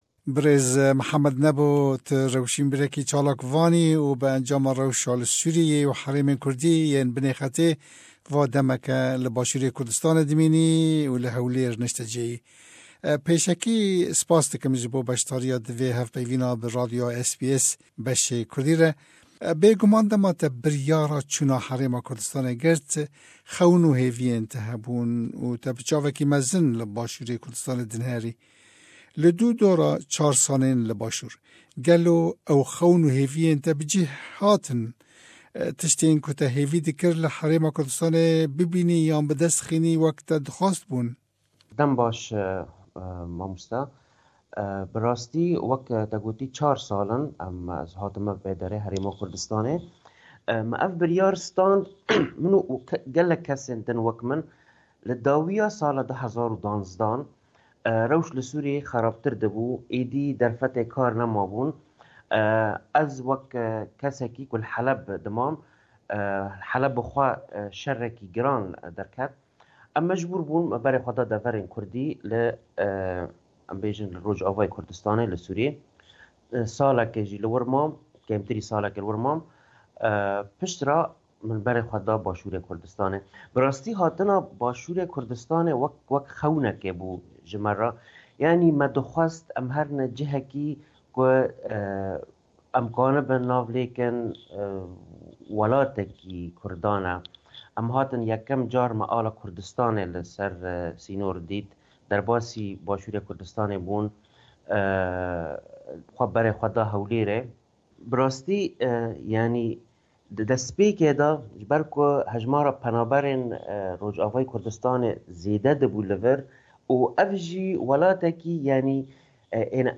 Kurdên Ji Sûriyeyê chûnî bashûr: Rewsha wan… - Hevpeyvîn